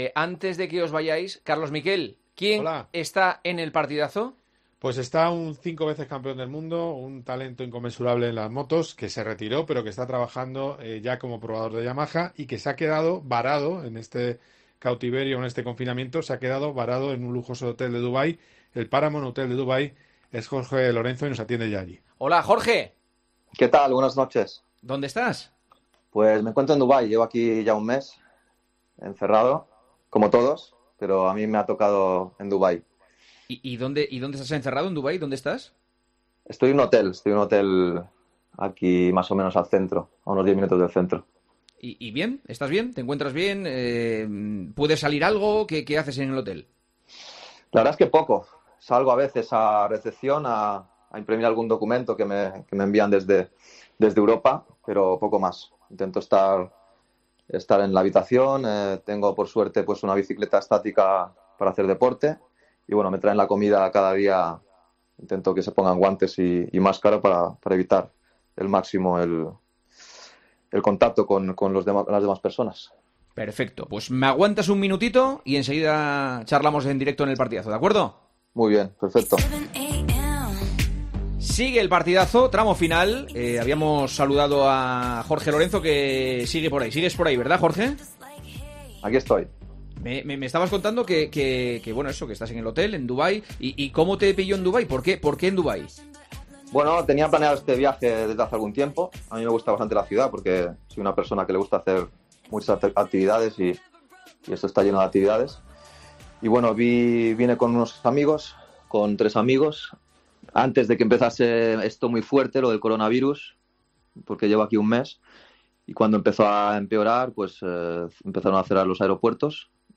AUDIO: Entrevistamos en El Partidazo de COPE a Jorge Lorenzo, ex piloto de MotoGP y actual piloto probador de Yamaha.